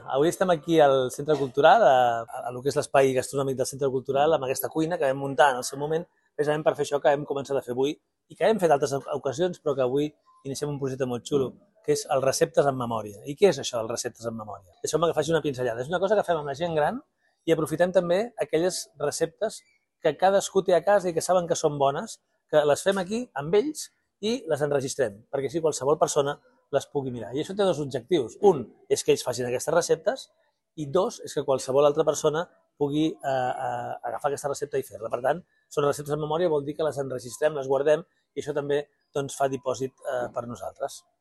Xavier Fonollosa, alcalde de Martorell